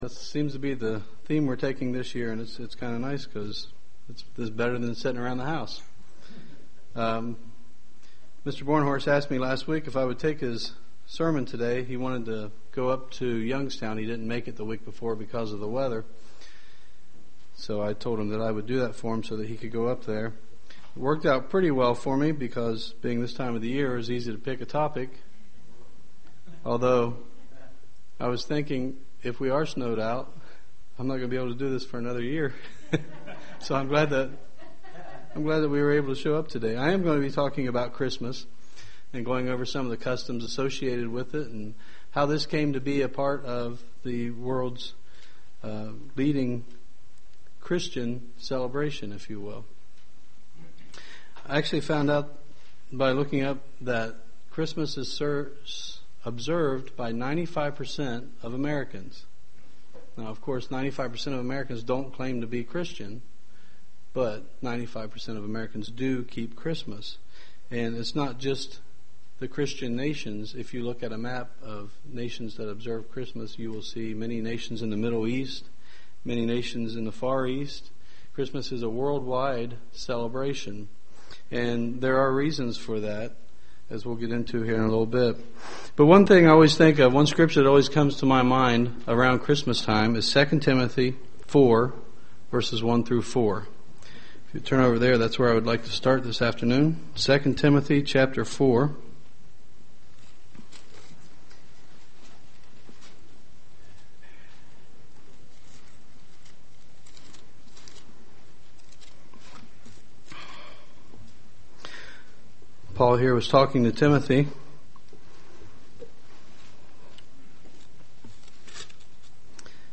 Many peole go through the tradtions of Christmas without the understading of what is behind it. In this sermon take a look at what Christmas is and what is behind it
Given in Dayton, OH